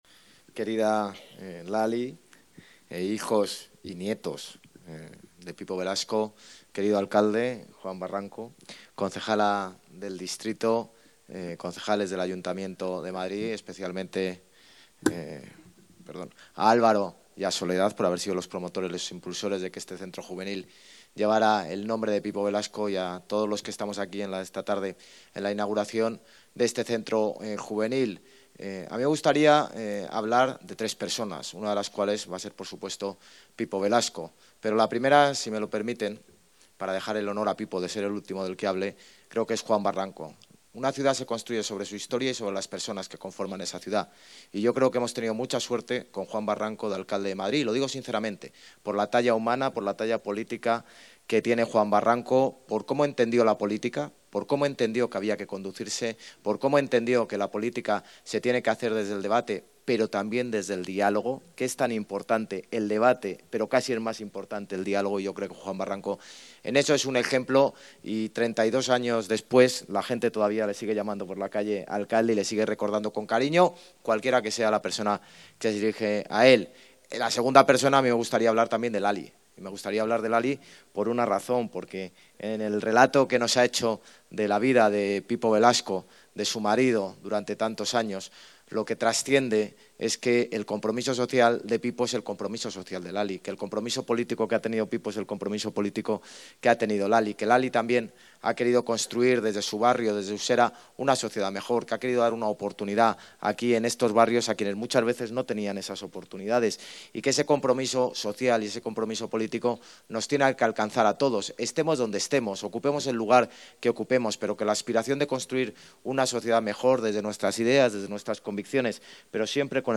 AUDIO-Intervencion-del-alcalde-inauguracion-Centro-Juvenil-Pipo-Velasco-en-Usera.mp3